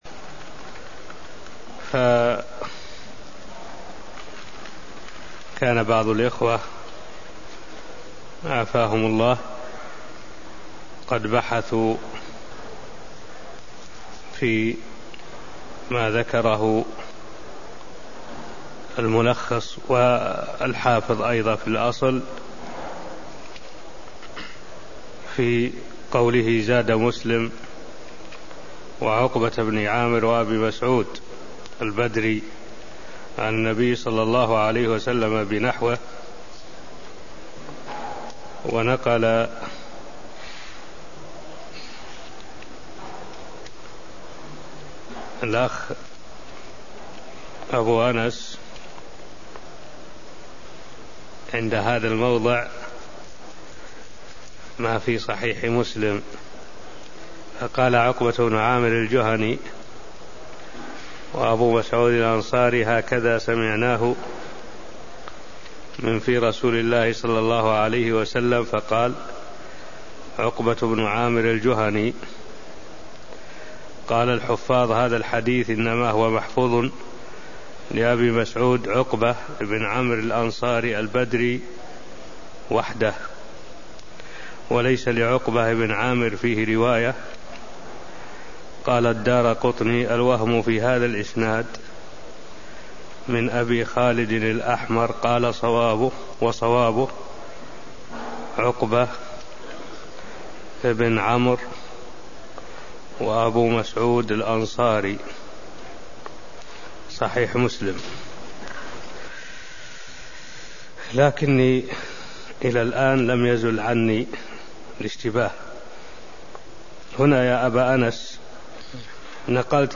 المكان: المسجد النبوي الشيخ: معالي الشيخ الدكتور صالح بن عبد الله العبود معالي الشيخ الدكتور صالح بن عبد الله العبود تفسير خواتيم سورة البقرة (0146)